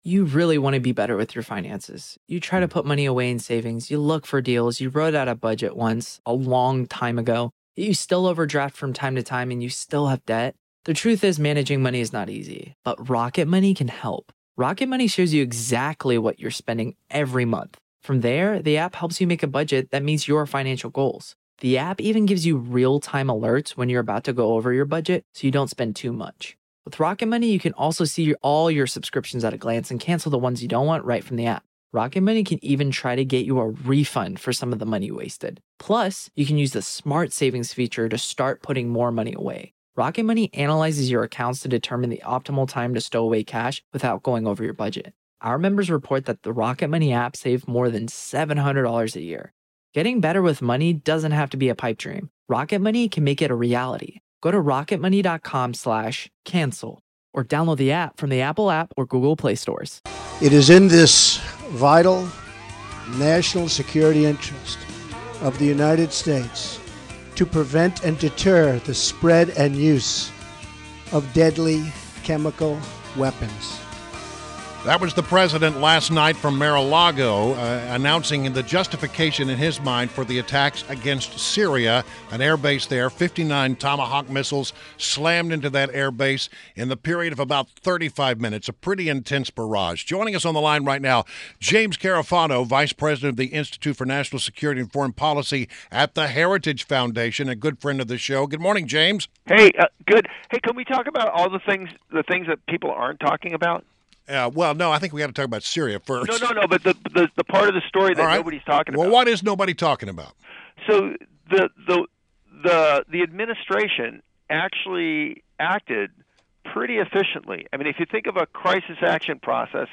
Mornings on the Mall / WMAL Interview